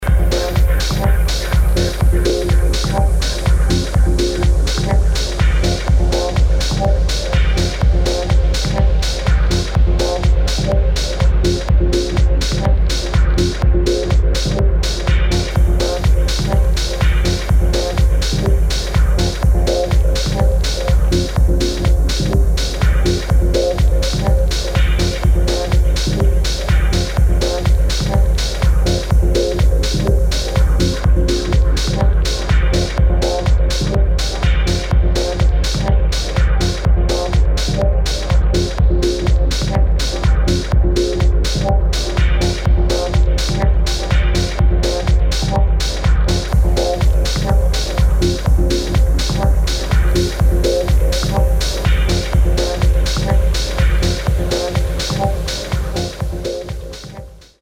[ TECHNO | MINIMAL ]